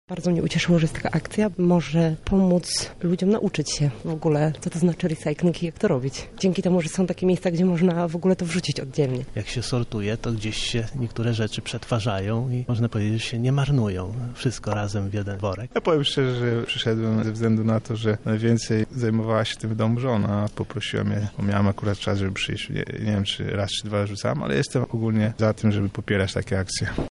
O tym dlaczego warto wziąć udział w tego typu wydarzeniach, mówią sami uczestnicy: